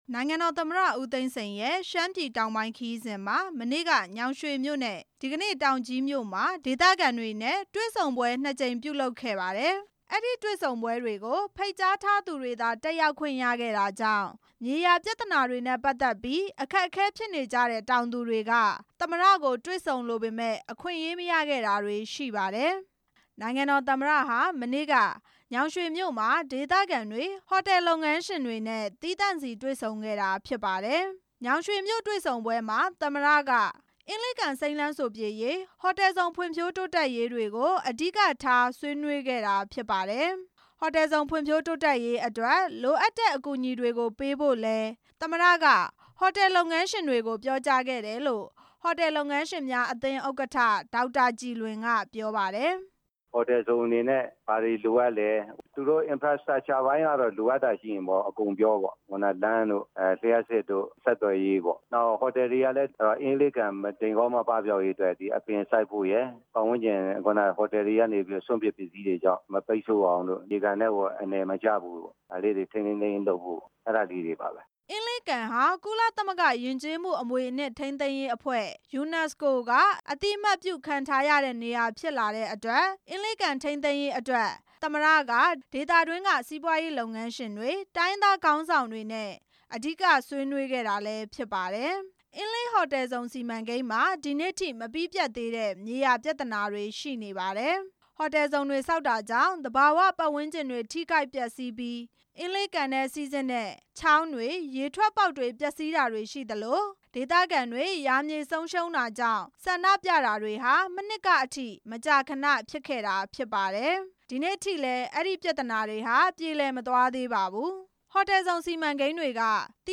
ရှမ်းပြည်နယ် တောင်ကြီးမြို့မှာ မြို့ခံတွေ၊ အစိုးရဌာနဆိုင်ရာ တာဝန်ရှိသူတွေနဲ့ ဒီကနေ့တွေ့ဆုံစဉ်မှာ သမ္မတဦးသိန်းစိန်က ပြောကြားခဲ့တာပါ။